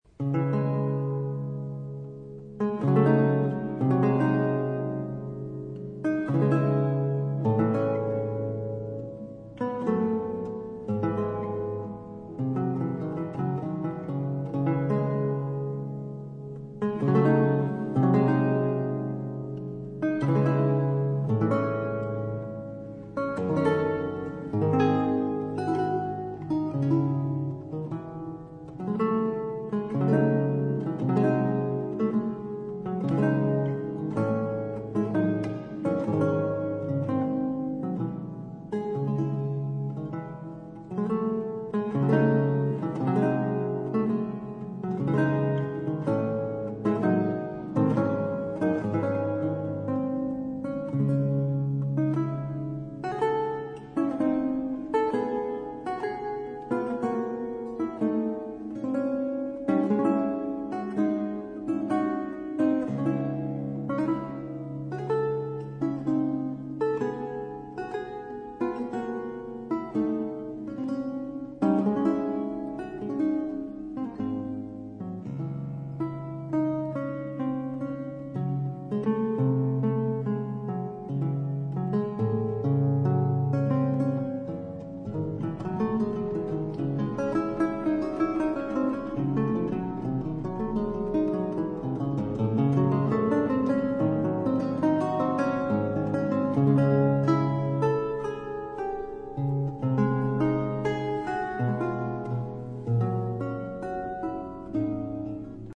平易好聽，可以細聽，也可以背景，